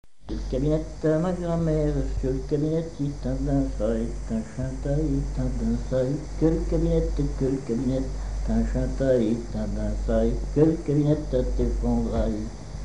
Couplets à danser
branle : courante, maraîchine
Pièce musicale inédite